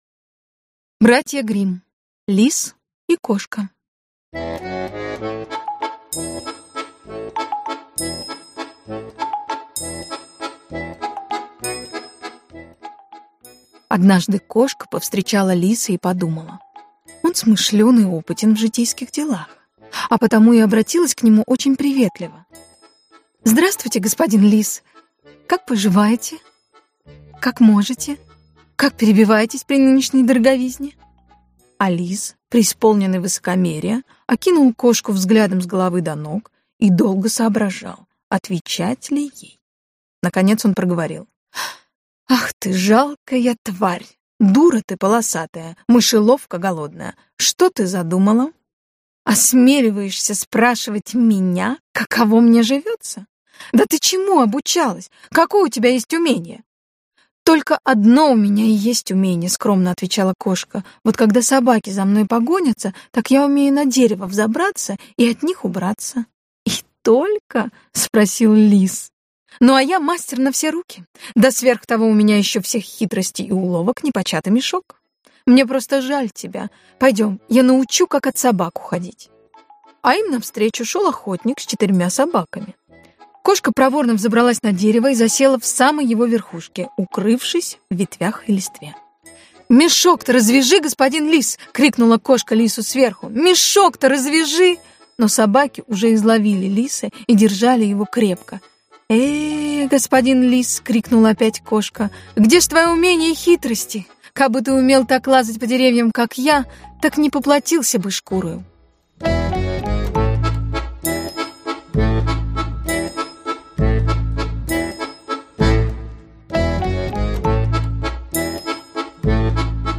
Аудиокнига ДЕТЯМ ЛУЧШЕЕ. Изумрудная книга сказок | Библиотека аудиокниг